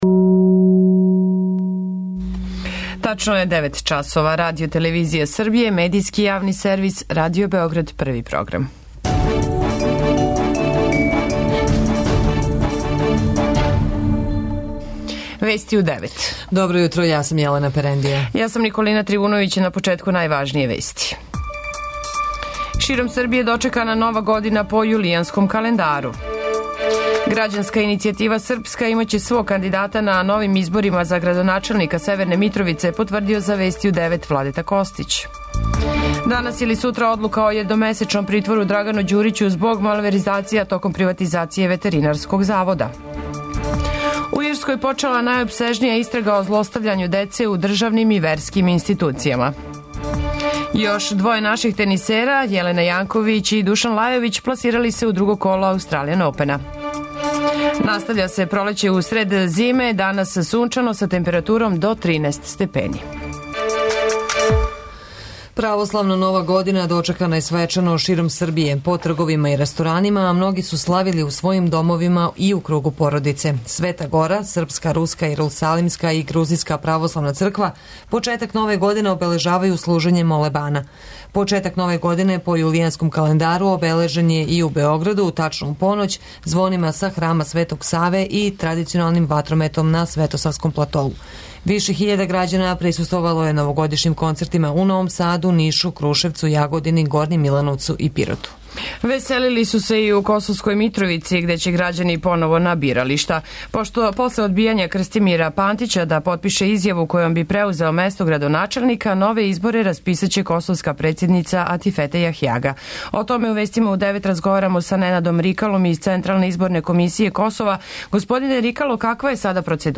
Данас суначано, са температуром до 13 степени. преузми : 9.76 MB Вести у 9 Autor: разни аутори Преглед најважнијиx информација из земље из света.